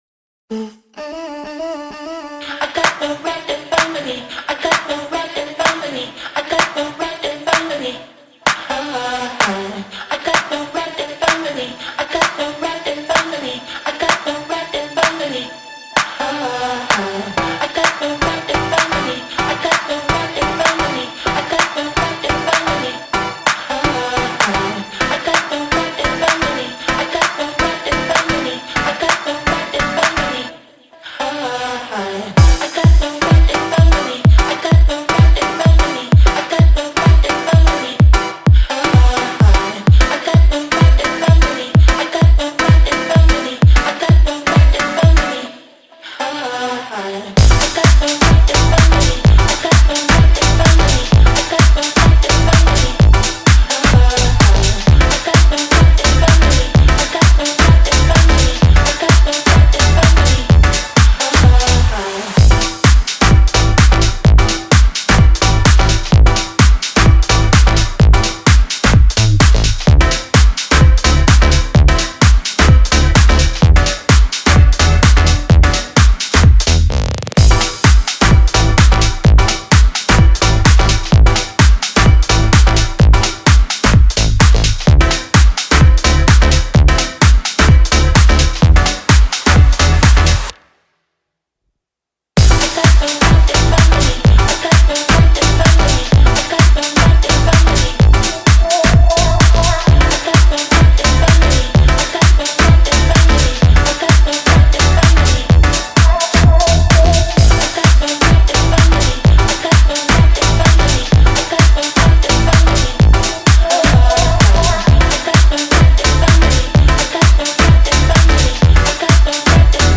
K-Pop Instrumentals